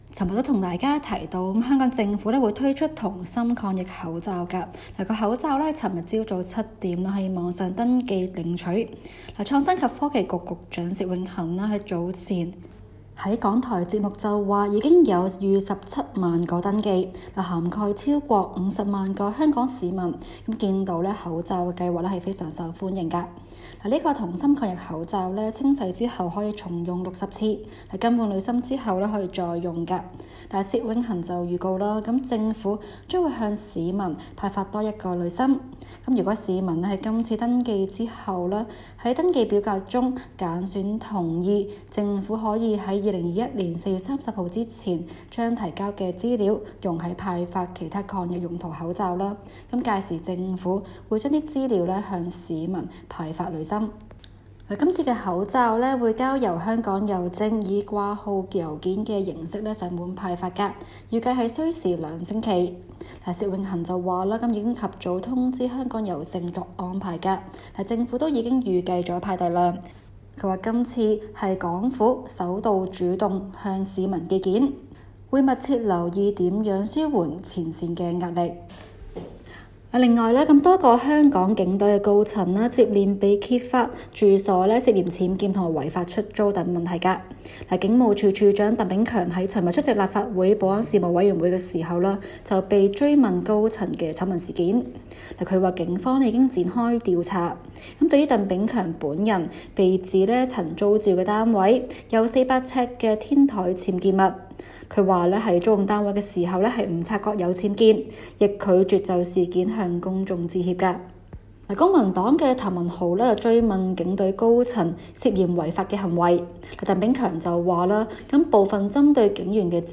今期 【中港快訊 】環節報道港府推出銅芯抗疫口罩，市民反應踴躍。